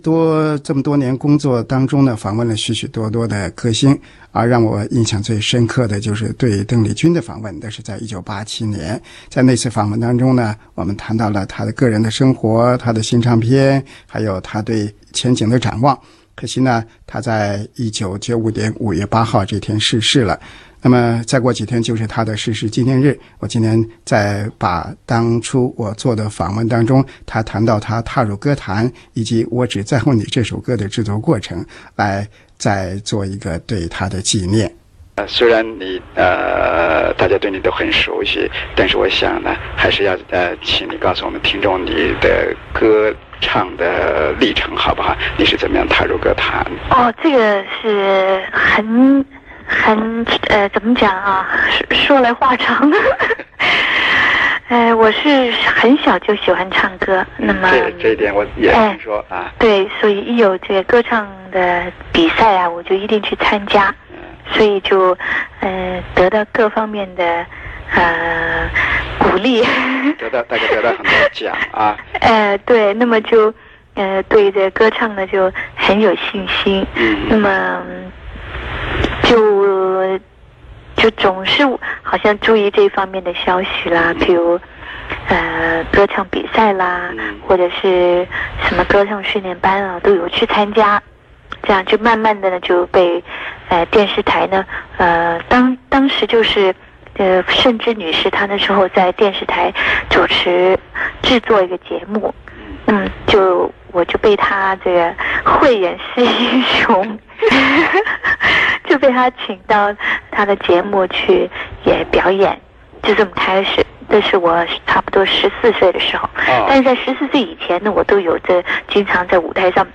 [7/8/2009]澳洲广播电台回忆当年电话采访【邓丽君】珍贵录音